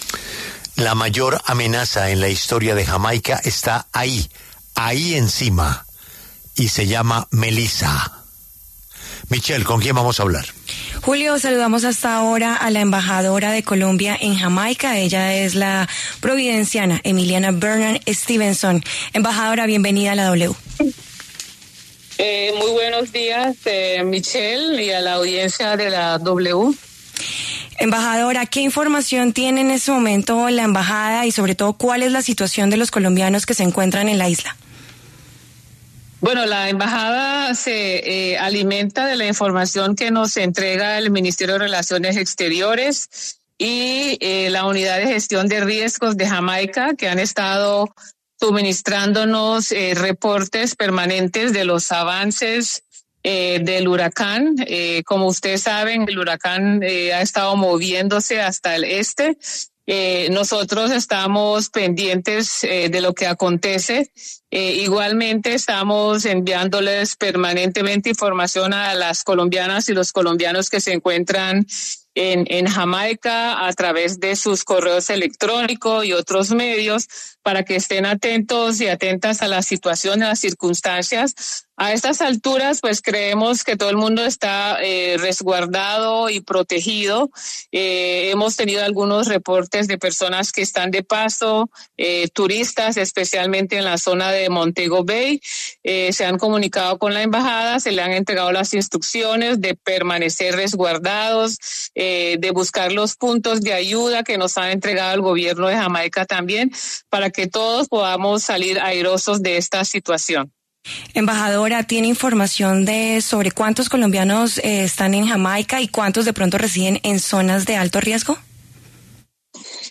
En diálogo con La W, la embajadora de Colombia en Jamaica, Emiliana Bernard Stephenson, entregó un reporte de los colombianos que están en ese país y cómo afrontan el paso del huracán Melissa.